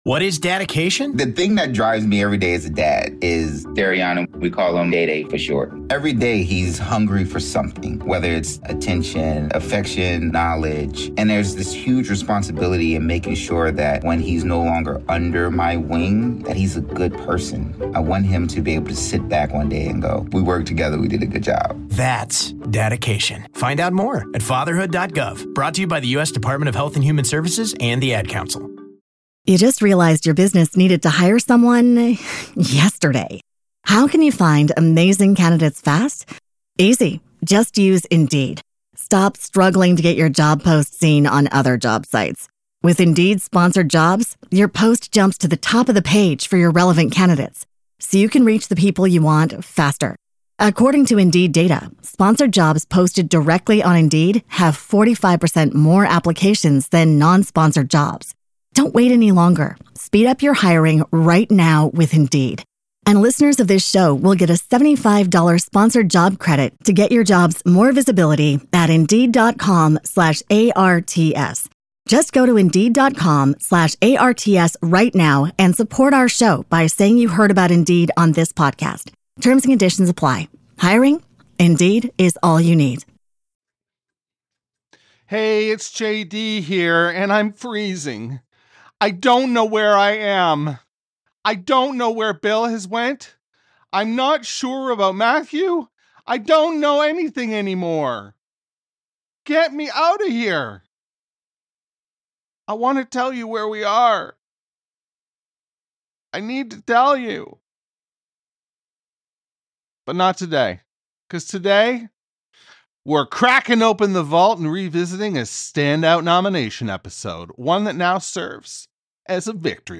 With passionate debates, candid insights, and plenty of laughs, this episode is packed with nostalgia for SNL fans.